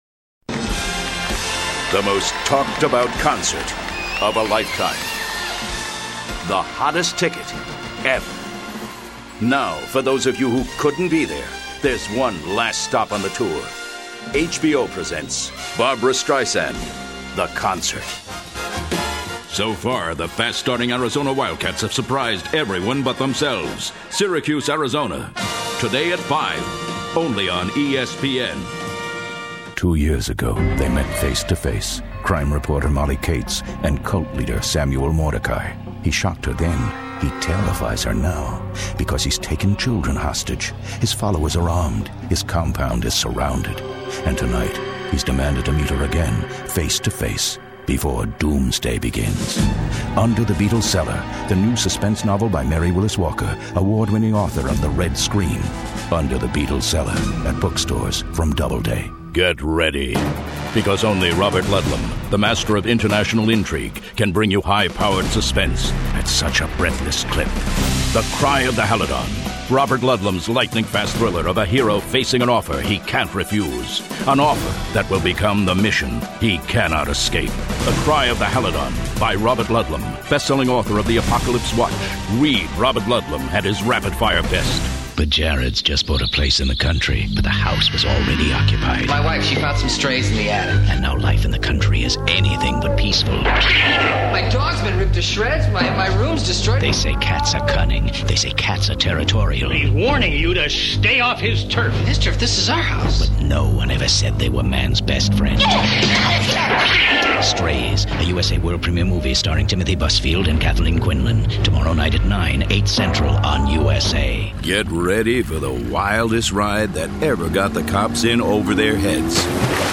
Commercial Voice | Promotional Voice